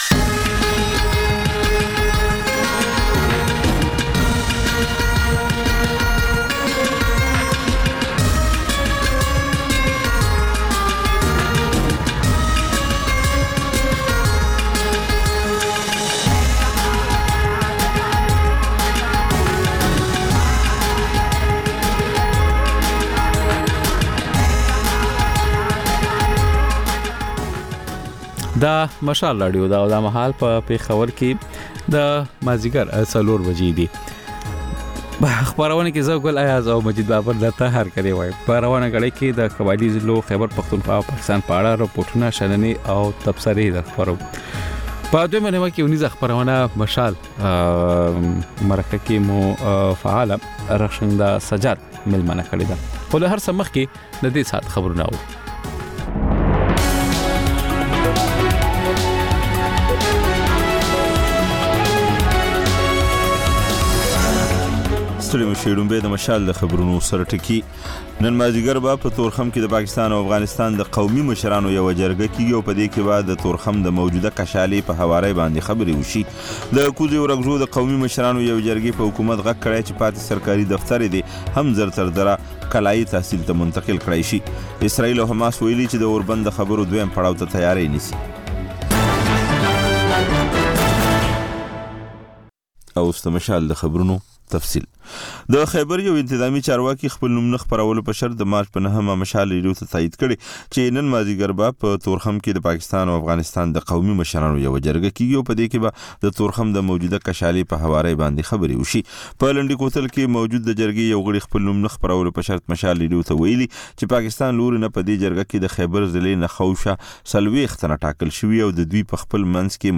د مشال راډیو مازیګرنۍ خپرونه. د خپرونې پیل له خبرونو کېږي، ورسره اوونیزه خپرونه/خپرونې هم خپرېږي.